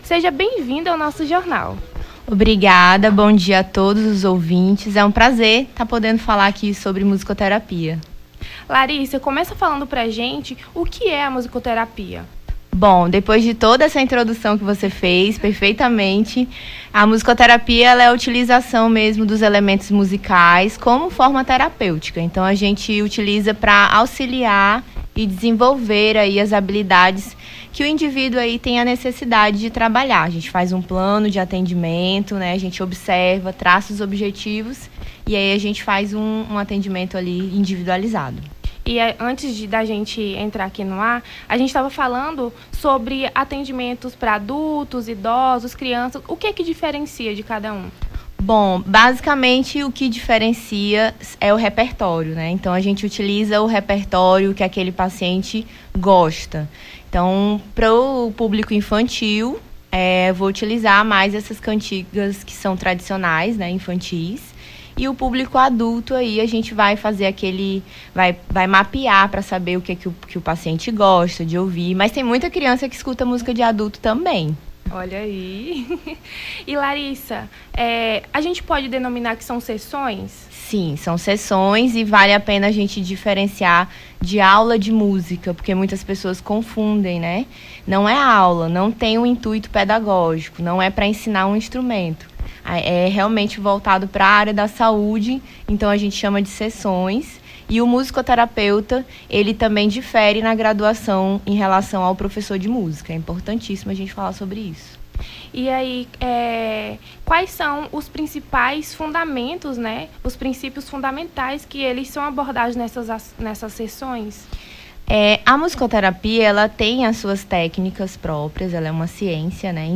Nome do Artista - CENSURA - ENTREVISTA (MUSICOTERAPIA) 18-08-23.mp3